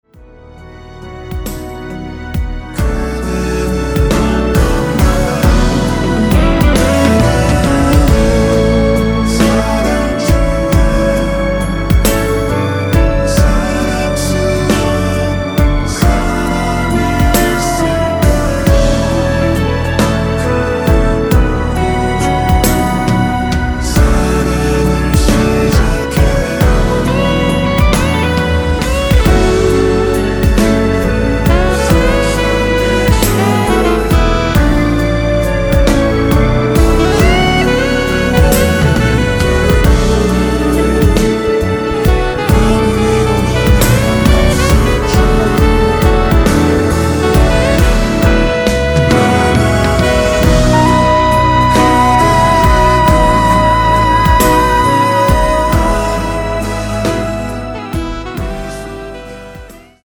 순수 코러스만 들어가 있으며 멤버들끼리 주고 받는 부분은 코러스가 아니라서 없습니다.(미리듣기 확인)
원키에서(-2)내린 멜로디와 코러스 포함된 MR입니다.(미리듣기 확인)
앞부분30초, 뒷부분30초씩 편집해서 올려 드리고 있습니다.
중간에 음이 끈어지고 다시 나오는 이유는